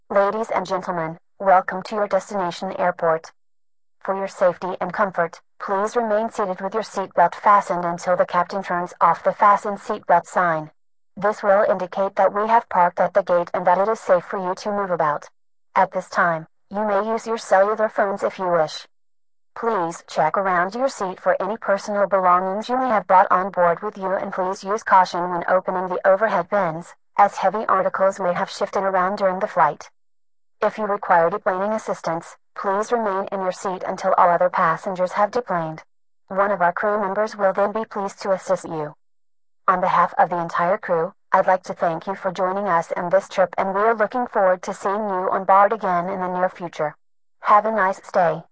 fa_landed.wav